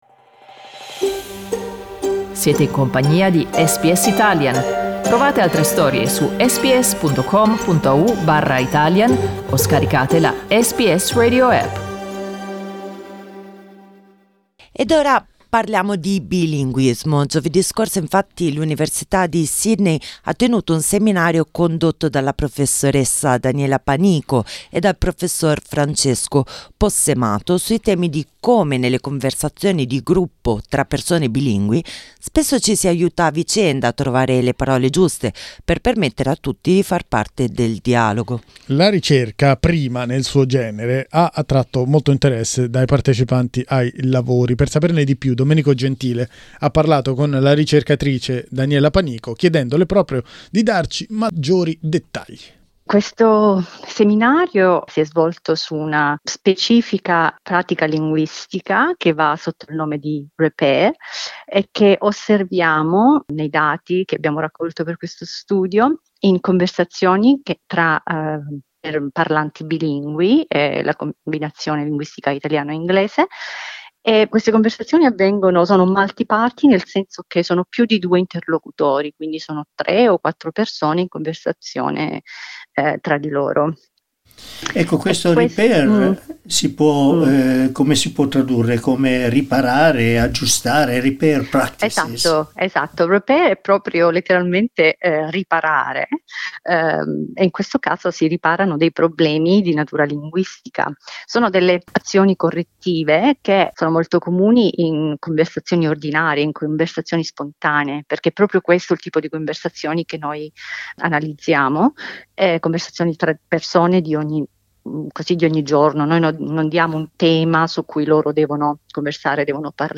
Riascolta l'intervista: LISTEN TO Strategie per insegnare l'italiano ai figli SBS Italian 10:40 Italian Le persone in Australia devono stare ad almeno 1,5 metri di distanza dagli altri.